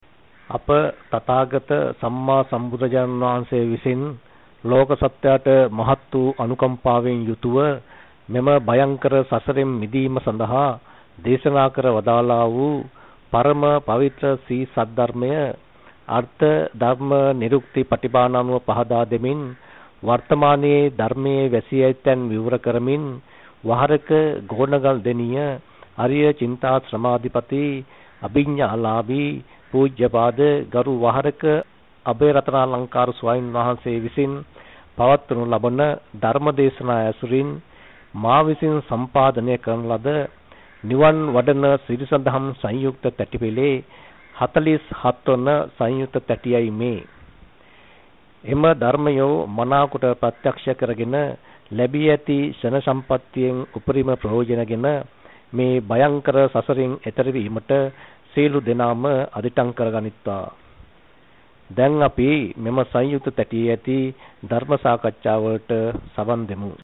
වෙනත් බ්‍රව්සරයක් භාවිතා කරන්නැයි යෝජනා කර සිටිමු 01:07 10 fast_rewind 10 fast_forward share බෙදාගන්න මෙම දේශනය පසුව සවන් දීමට අවැසි නම් මෙතැනින් බාගත කරන්න  (1 MB)